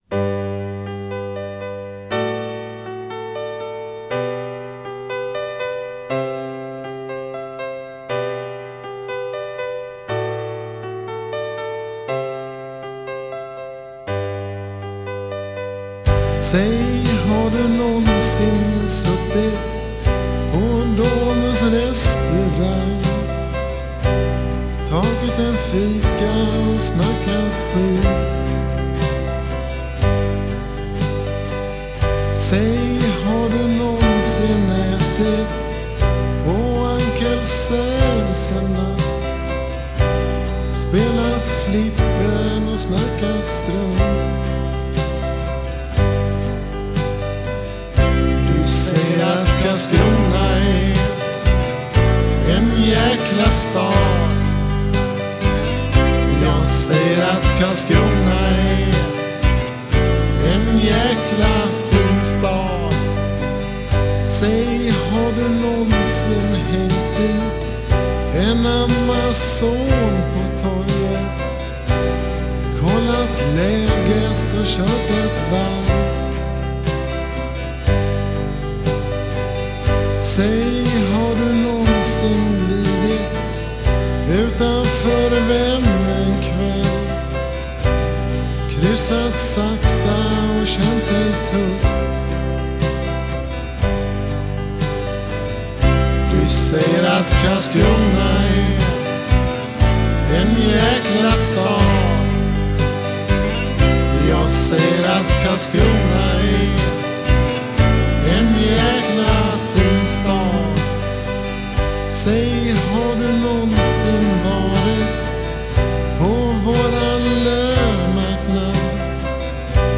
Onödigt långt instrumentaloutro.